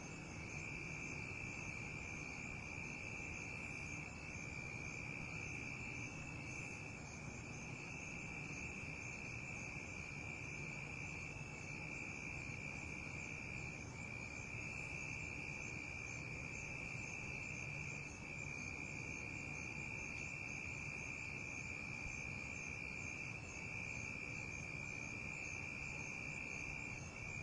蟋蟀树夜
描述：晚上在树上发现的蟋蟀。
Tag: 蟋蟀